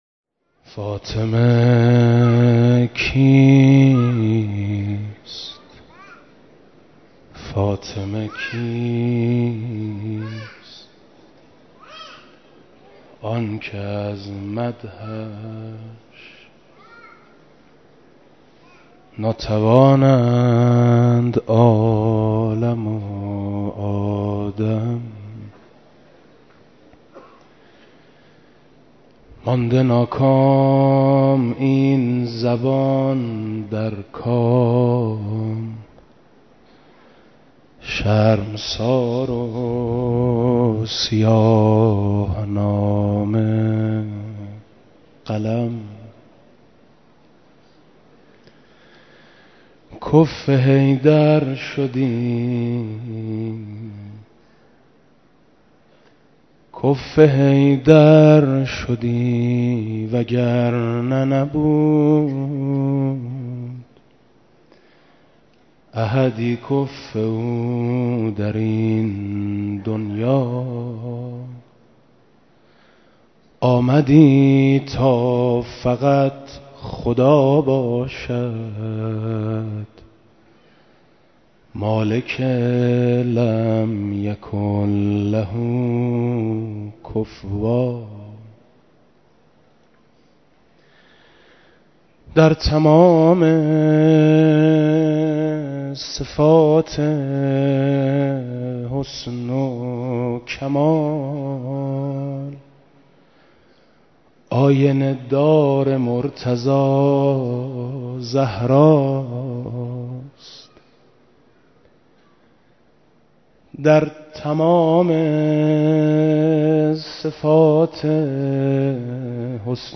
مراسم عزاداری شام شهادت حضرت فاطمه زهرا سلام‌الله‌علیها
مداحی